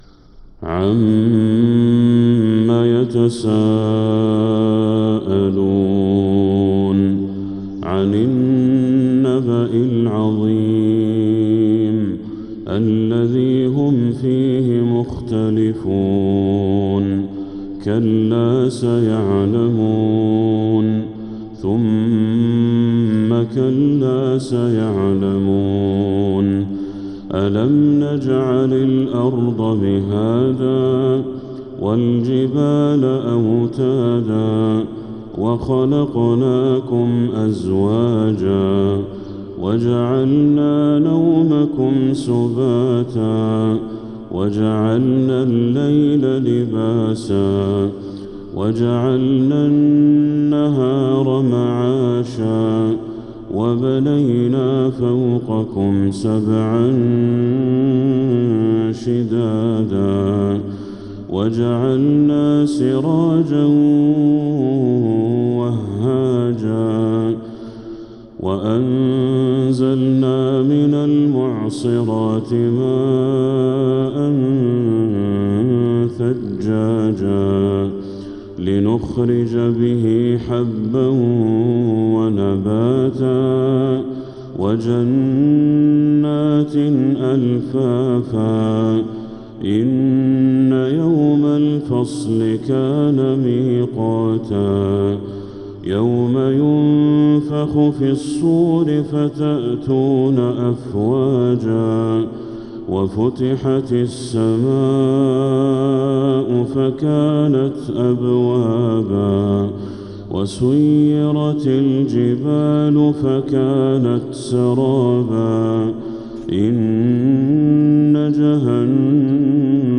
سورة النبأ كاملة | جمادى الاخرة 1446هـ > السور المكتملة للشيخ بدر التركي من الحرم المكي 🕋 > السور المكتملة 🕋 > المزيد - تلاوات الحرمين